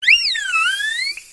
339Cry.wav